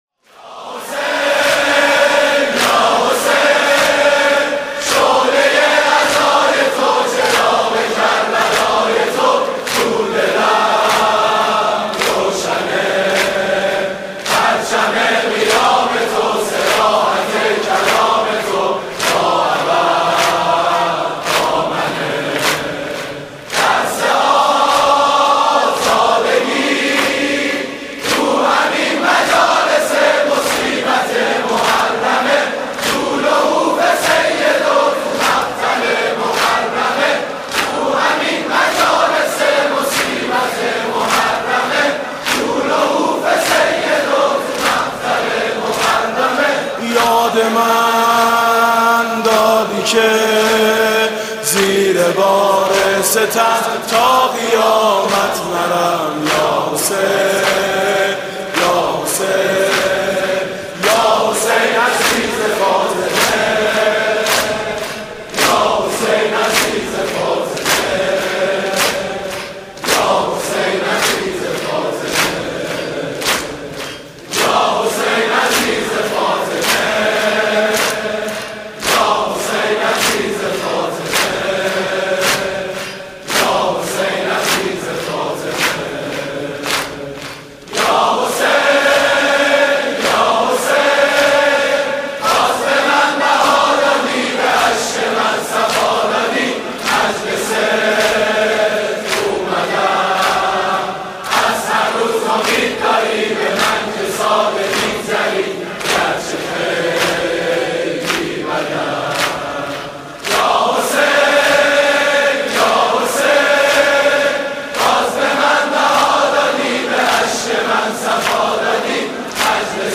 «محرم 1396» (شب یازدهم) دم پایانی: یا حسین یا حسین، من کجا لیاقت اقامه عزای تو؟